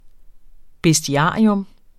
Udtale [ besdiˈɑˀiɔm ]